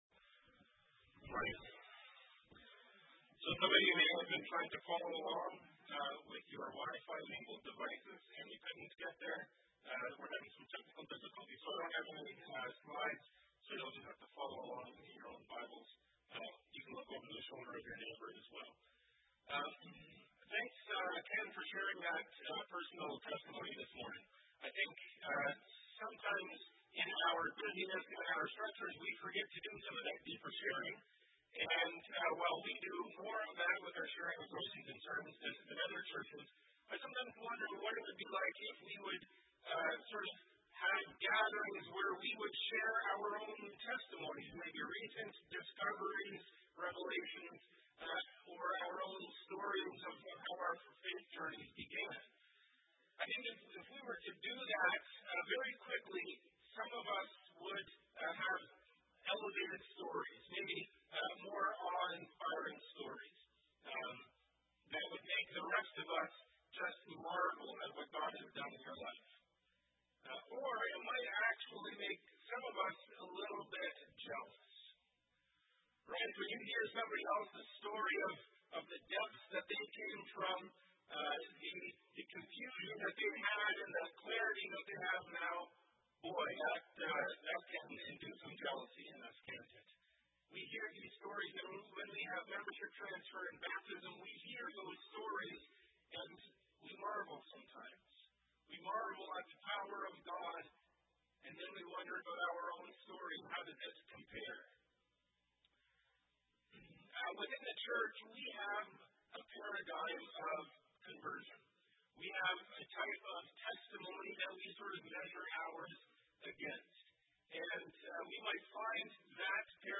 Wrestling Sermon Series – Week 1